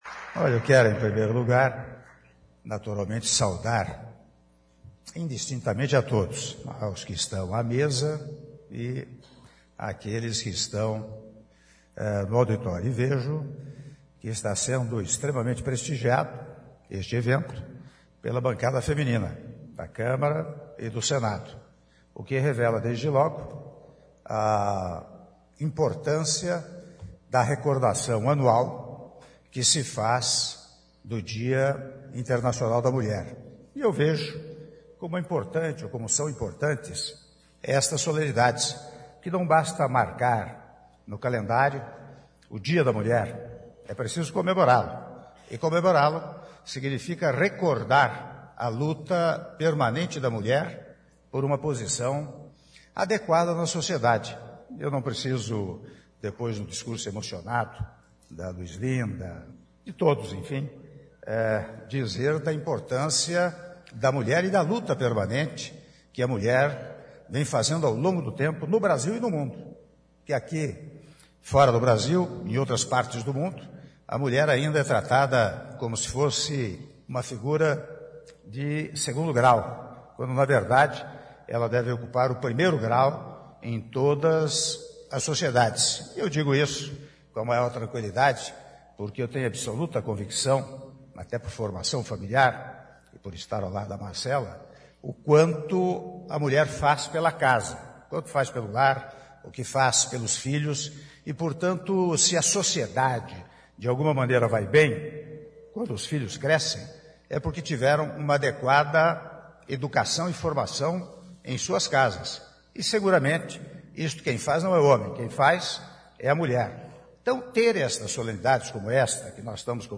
Áudio do discurso do Presidente da República, Michel Temer, durante cerimônia de Comemoração pelo Dia Internacional da Mulher - Brasília/DF (10min55s)